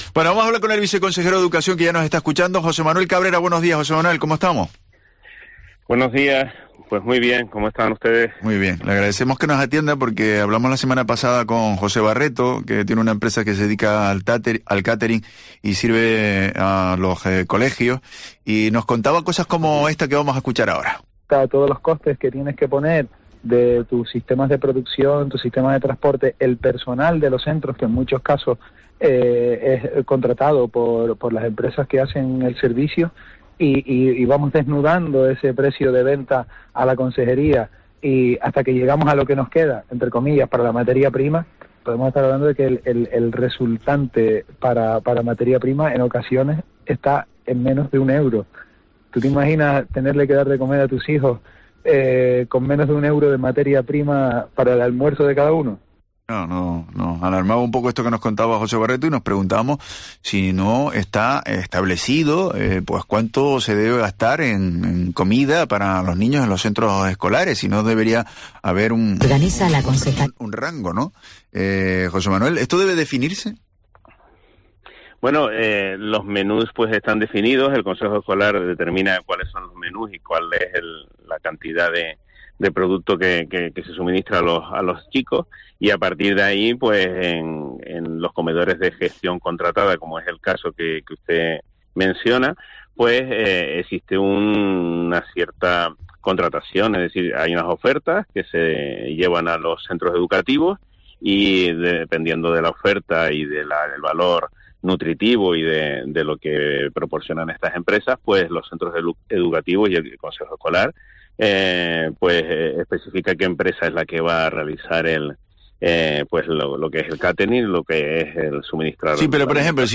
Lo ha hecho en los micrófonos de Herrera en COPE Canarias, al hilo de las quejas surgidas en los últimos días respecto a su pobre calidad y precio.